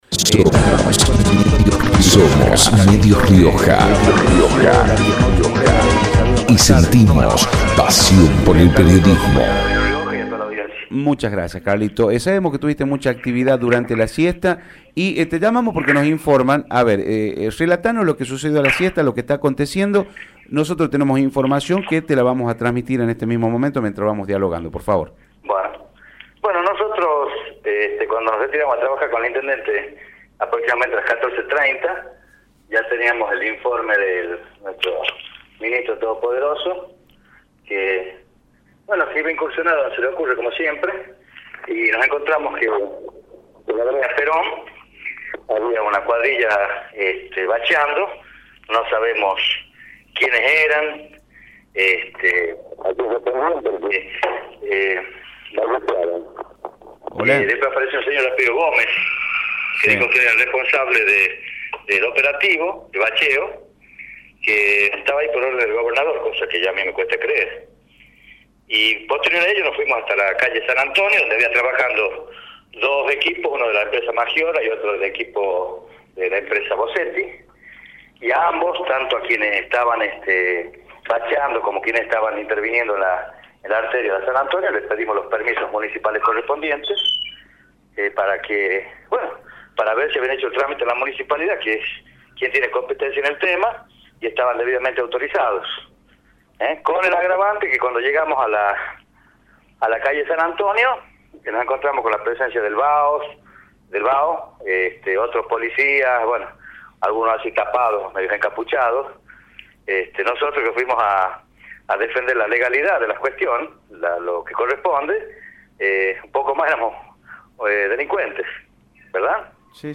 Carlos Machicote, secretario de Servicios Públicos, por Radio Rioja